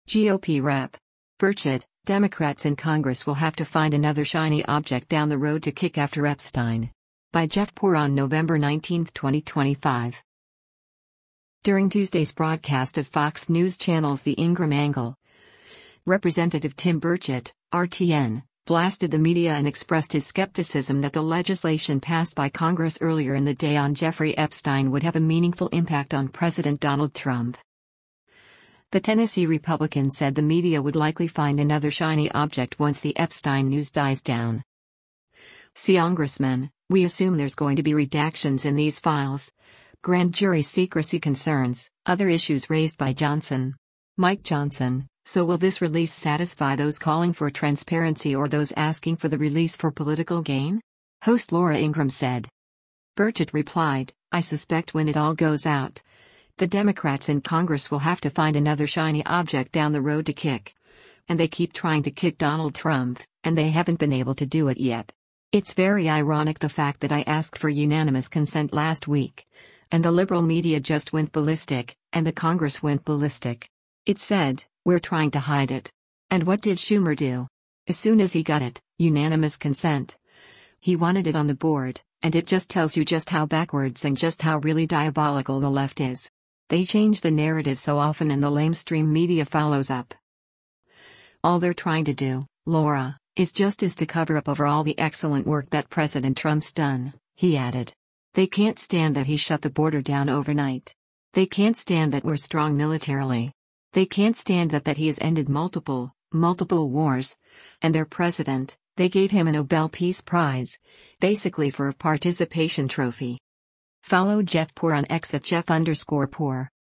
During Tuesday’s broadcast of Fox News Channel’s “The Ingraham Angle,” Rep. Tim Burchett (R-TN) blasted the media and expressed his skepticism that the legislation passed by Congress earlier in the day on Jeffrey Epstein would have a meaningful impact on President Donald Trump.